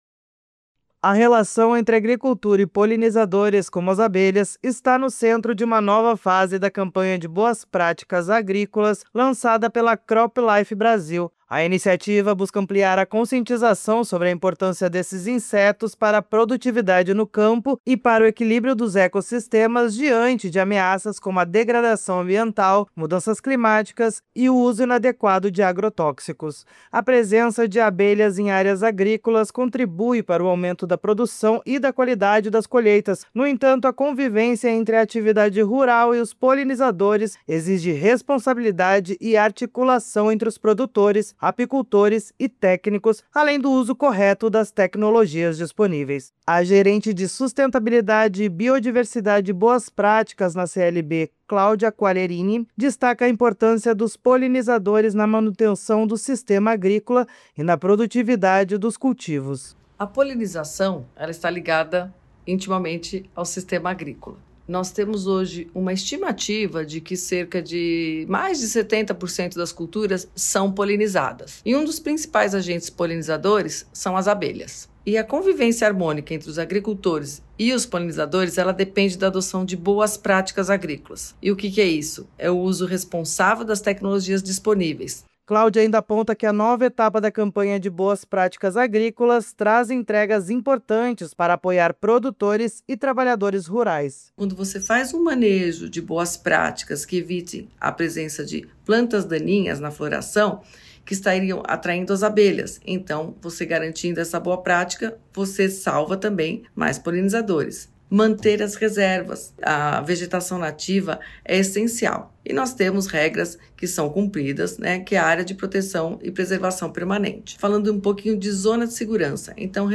[Rádio] Campanha orienta proteção às abelhas - CropLife